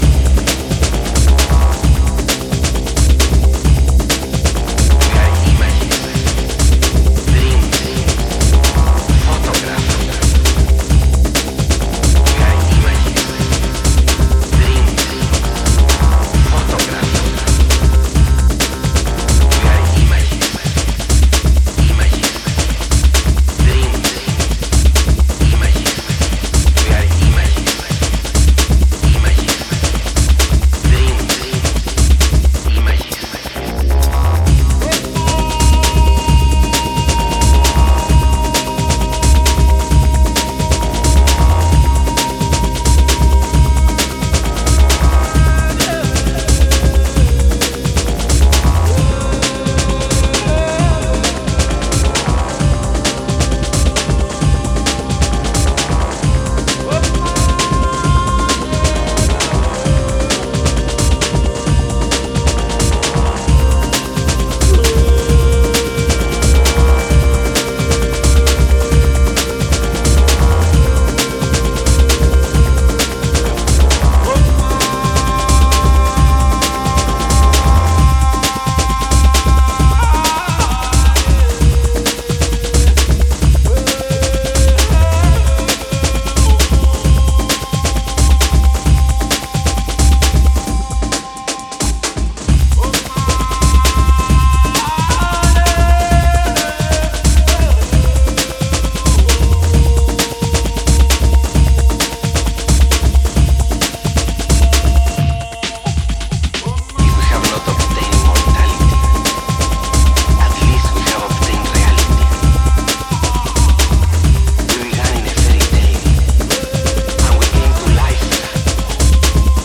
民族チャントも気分を盛り上げるクラシカルなブレイクビーツローラー